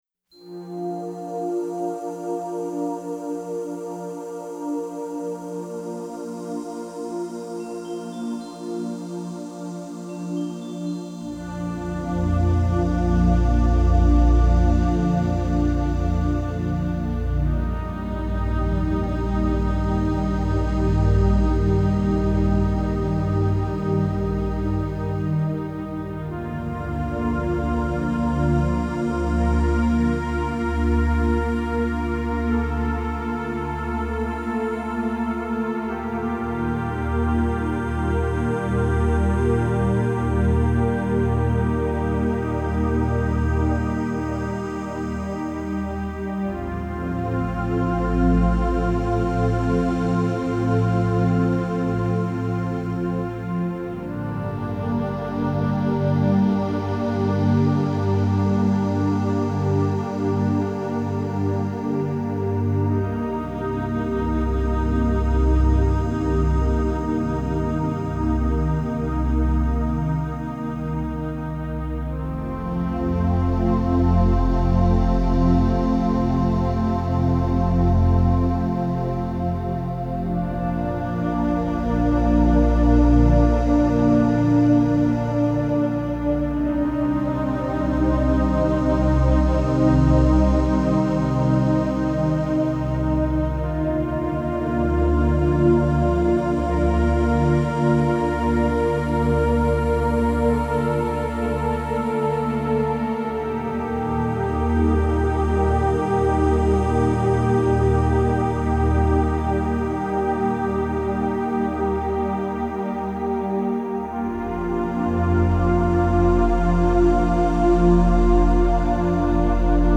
Calming music for meditation, relaxation, and stress relief.